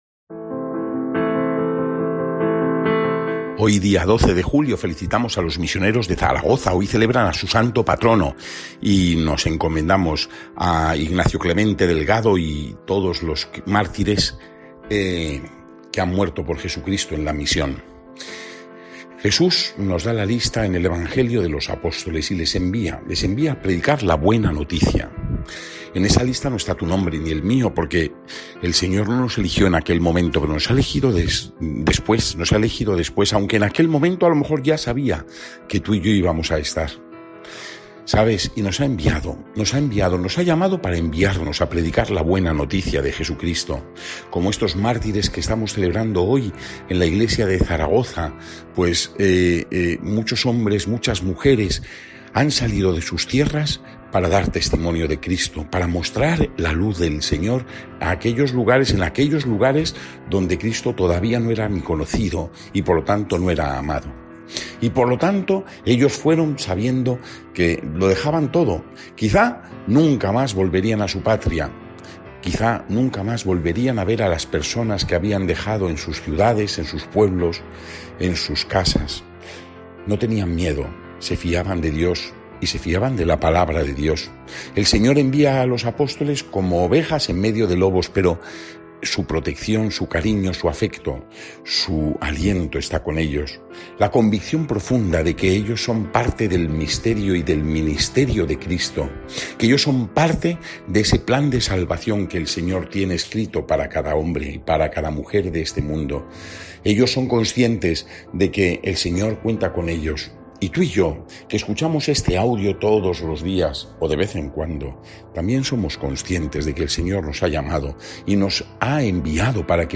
Evangelio según san Mateo (10,1-7) y comentario